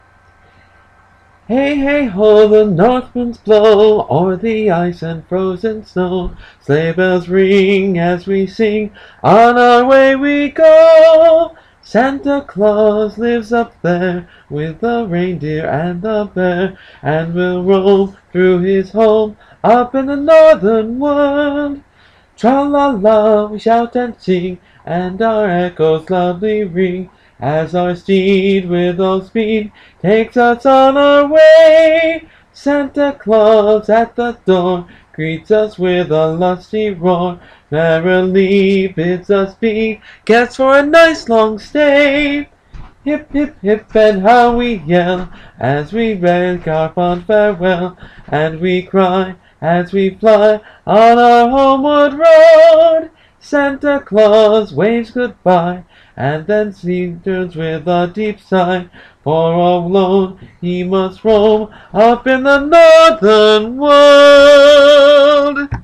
This is one of the Santa carols we do, and not one most people know these days.
It is fun and merry.